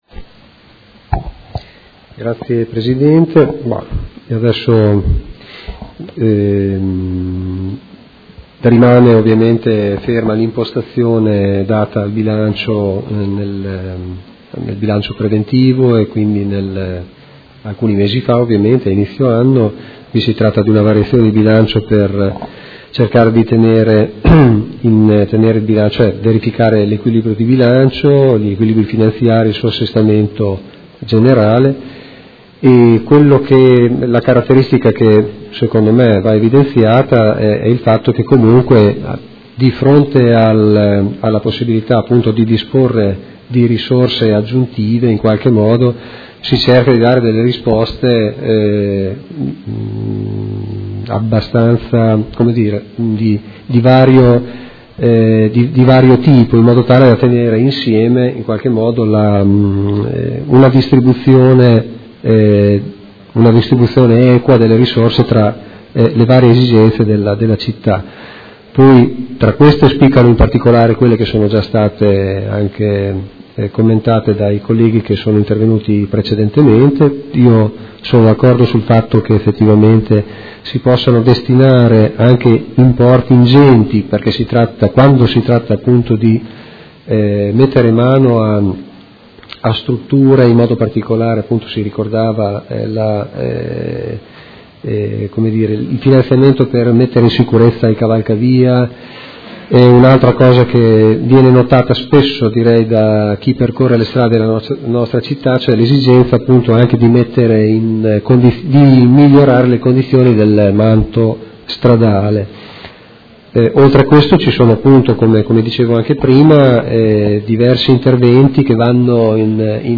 Marco Malferrari — Sito Audio Consiglio Comunale
Seduta del 20/07/2017 Dibattito.